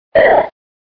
Cri de Makuhita dans Pokémon Diamant et Perle.